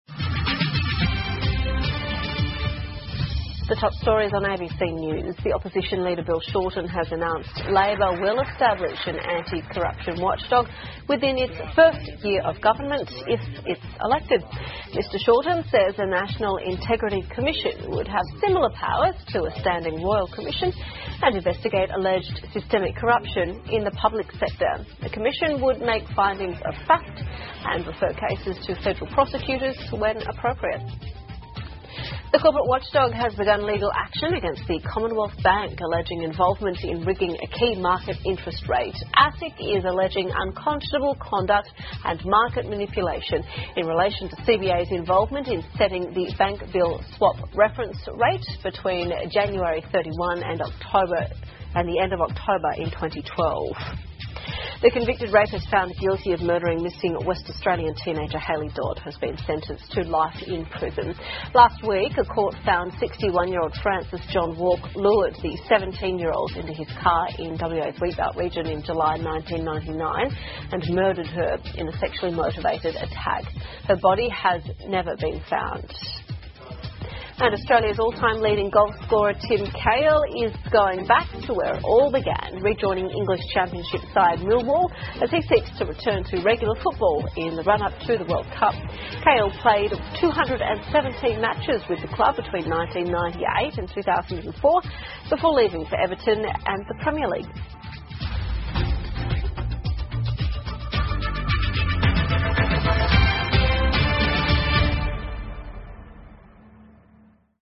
澳洲新闻 (ABC新闻快递) 澳工党承诺胜选后成立反腐机构 传奇球星卡希尔将加盟英冠老东家 听力文件下载—在线英语听力室